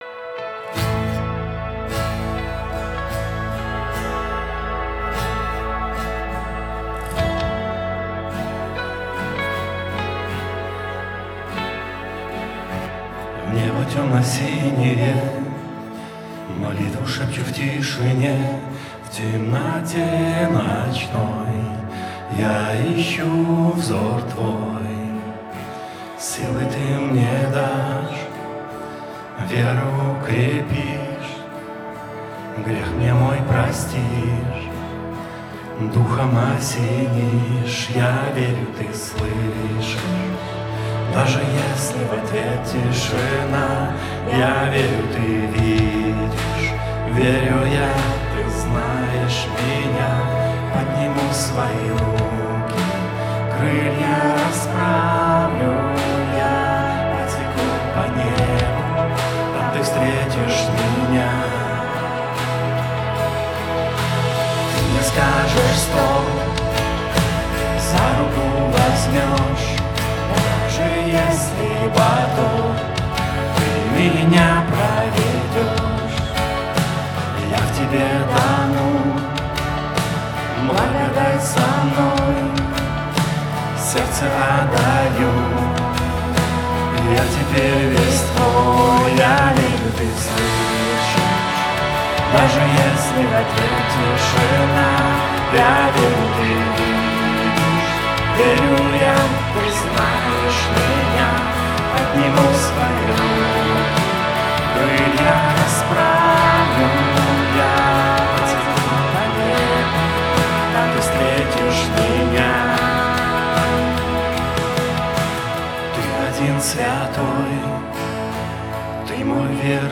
264 просмотра 546 прослушиваний 7 скачиваний BPM: 75